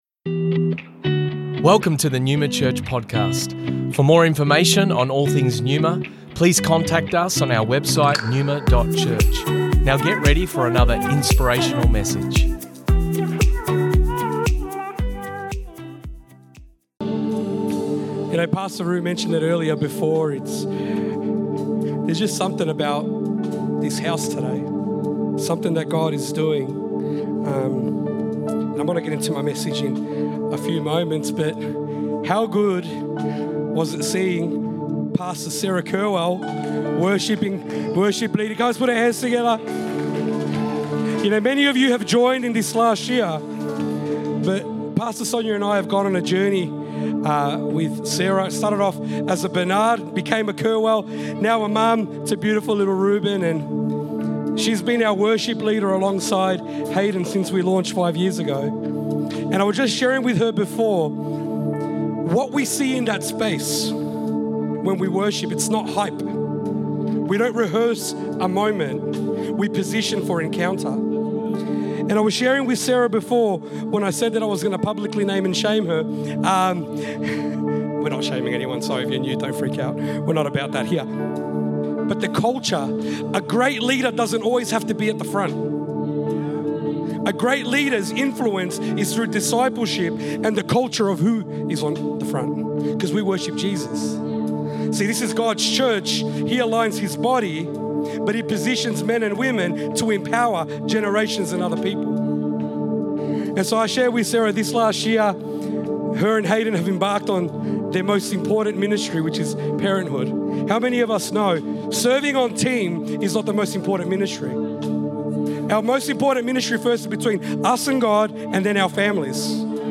Originally recorded at Neuma Melbourne West 2023 Sunday 26th November